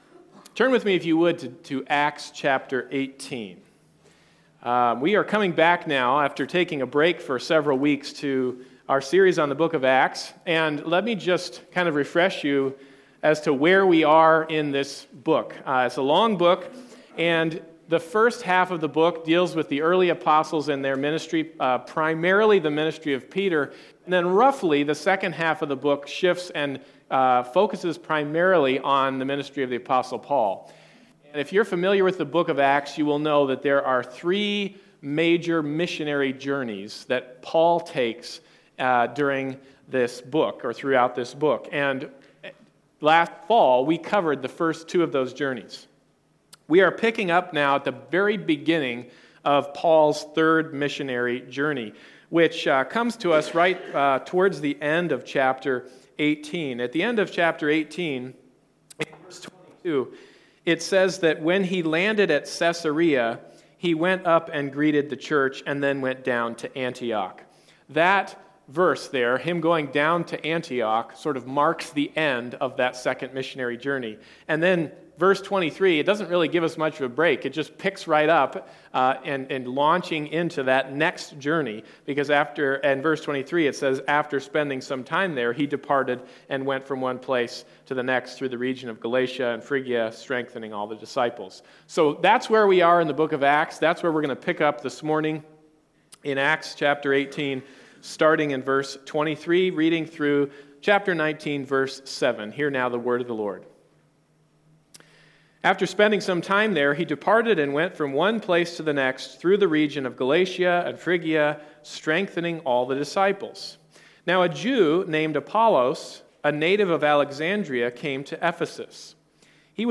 19:7 Service Type: Sunday Morning Service « What is an Elder?